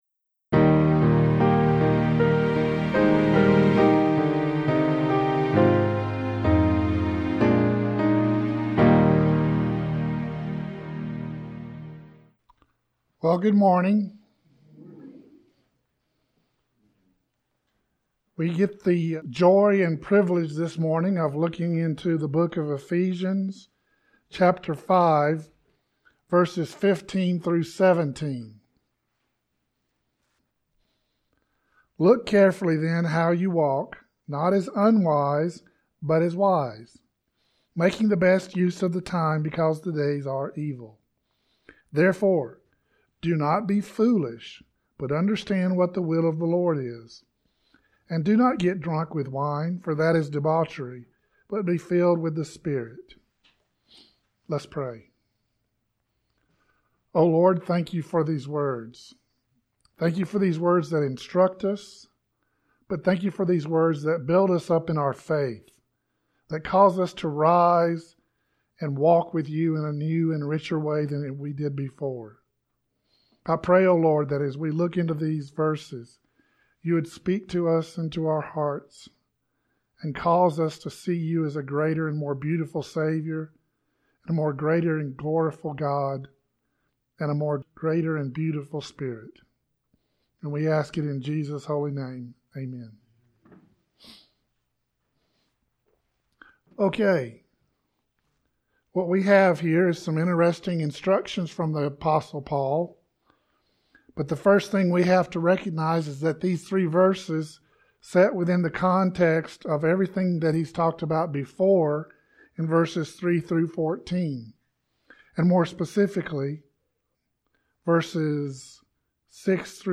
Current Sermon | Castle Rock Baptist Church